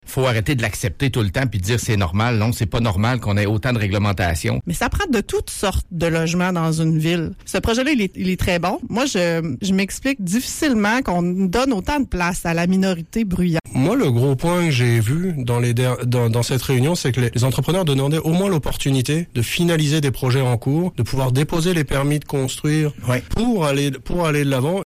À lire aussi : La Ville de Rimouski rencontre des constructeurs et promoteurs À lire aussi : La Chambre de commerce approuve le projet Le Phare De nombreux intervenants ont défilé sur nos ondes, à ce sujet, ce matin.